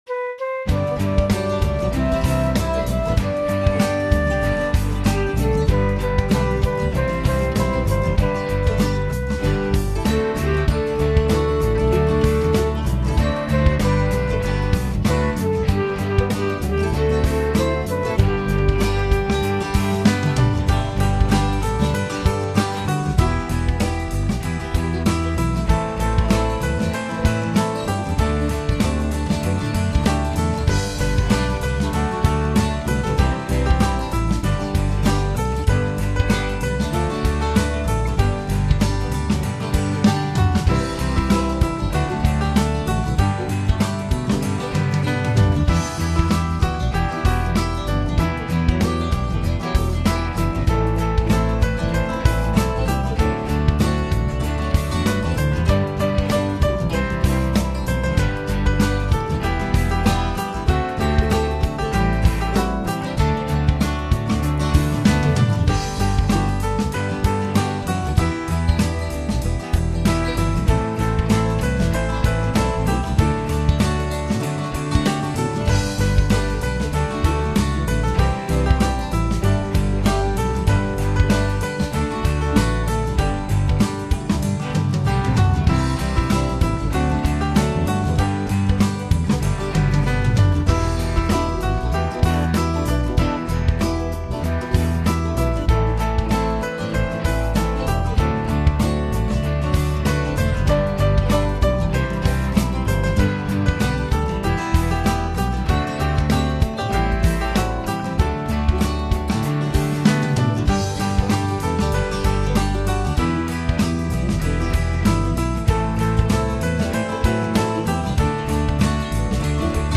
I made a backing with more country rock than folk: